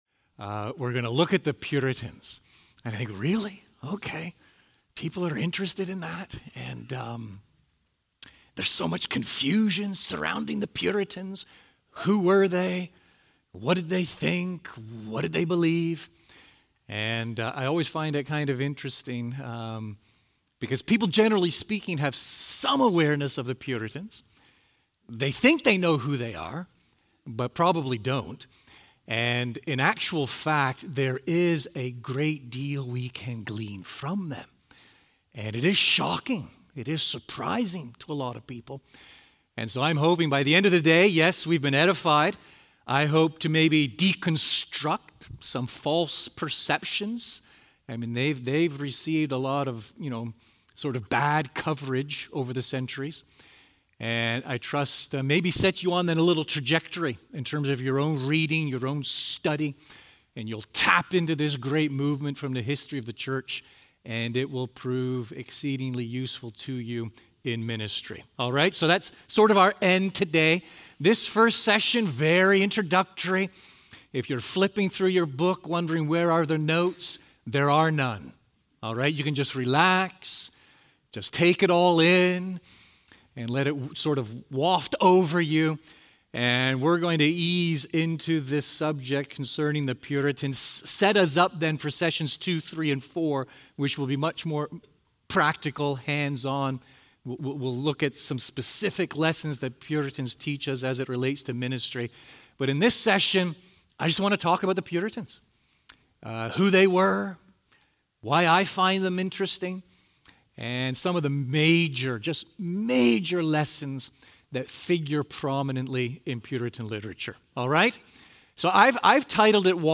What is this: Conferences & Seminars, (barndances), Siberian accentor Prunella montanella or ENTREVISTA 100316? Conferences & Seminars